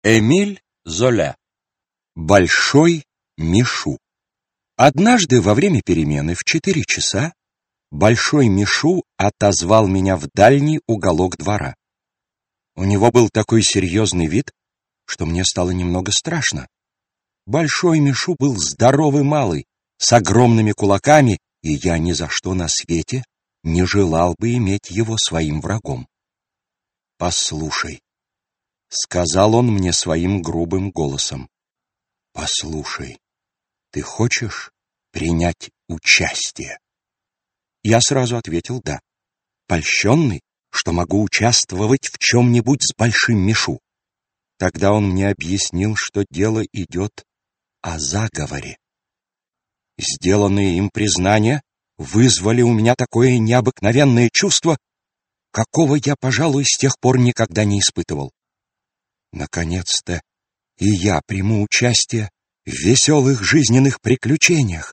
Аудиокнига Классика зарубежного рассказа № 1 | Библиотека аудиокниг
Aудиокнига Классика зарубежного рассказа № 1 Автор Сборник Читает аудиокнигу Александр Клюквин.